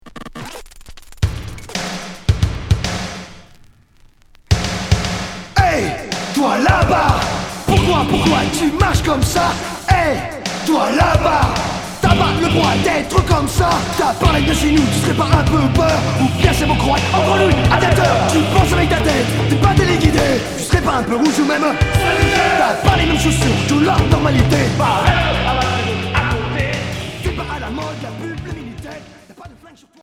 Punk rap